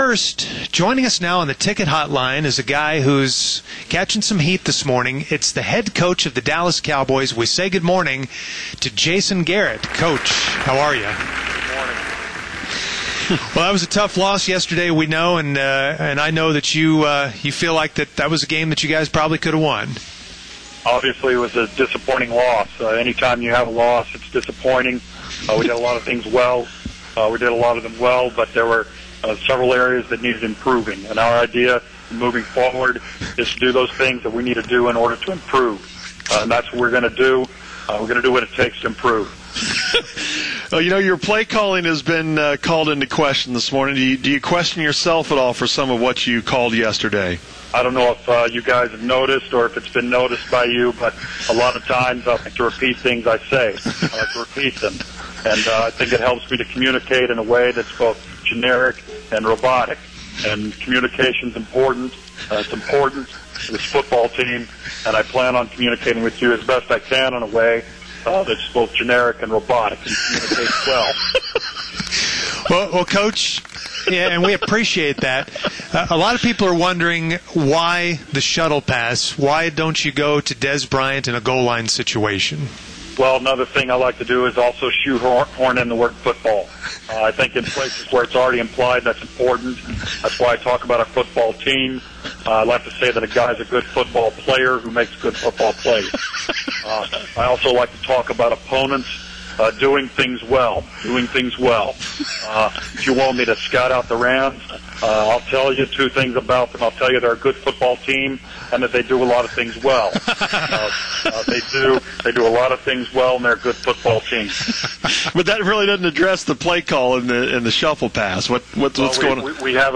Finally! The fake Jason Garrett has come out of hiding and talked with the Musers today.